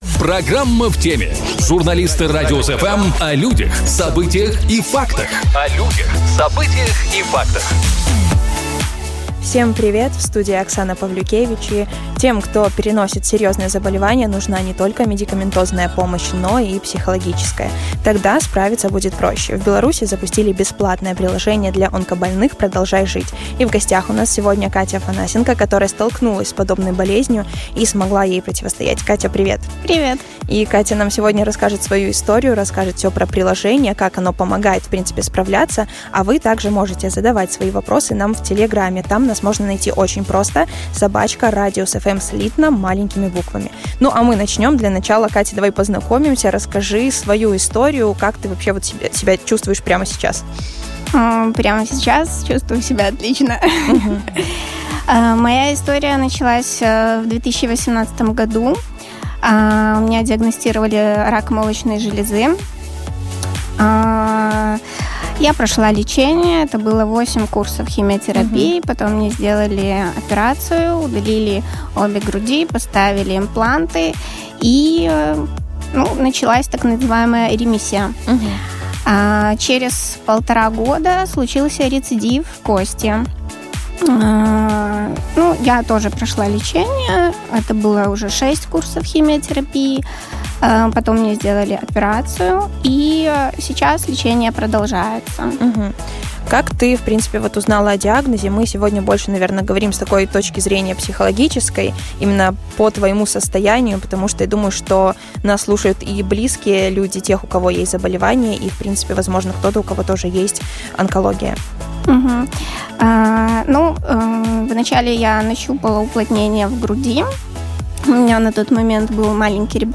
Жизнь с онкологией | Шоу с толком | Радиус-FM